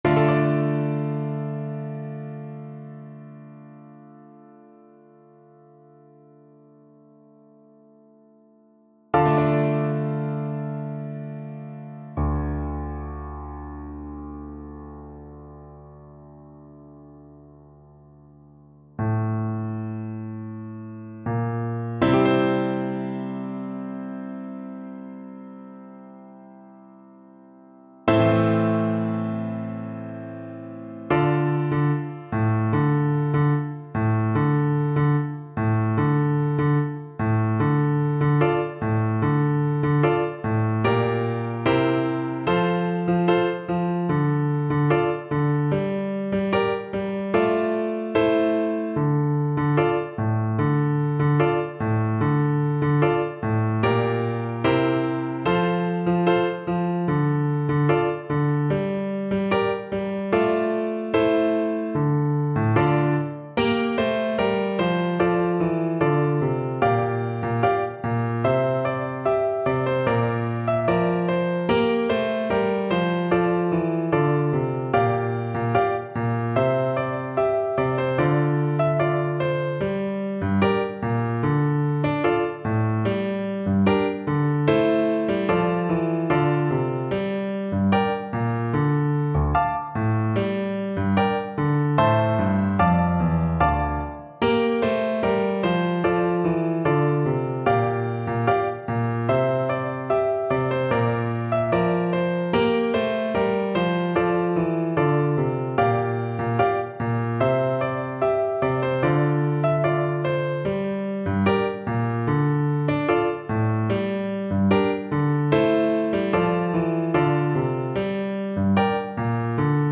Play (or use space bar on your keyboard) Pause Music Playalong - Piano Accompaniment Playalong Band Accompaniment not yet available transpose reset tempo print settings full screen
Flute
2/4 (View more 2/4 Music)
F major (Sounding Pitch) (View more F major Music for Flute )
Slow